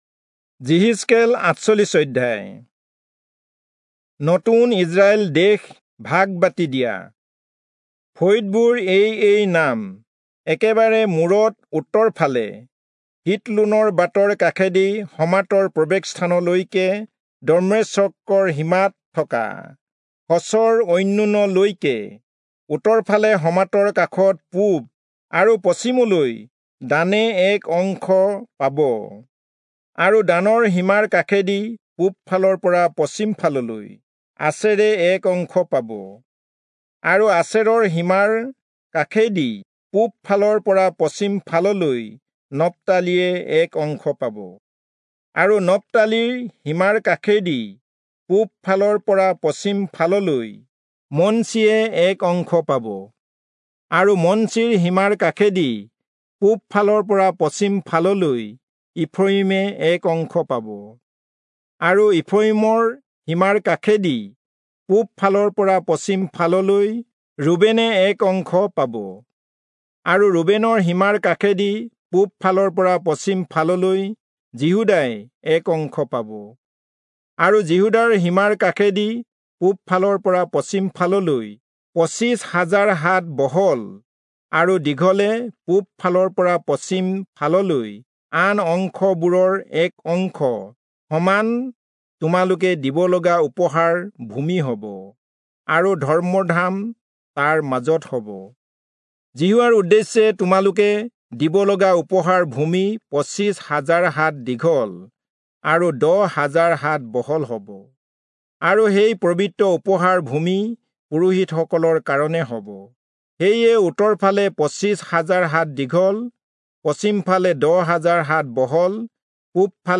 Assamese Audio Bible - Ezekiel 11 in Knv bible version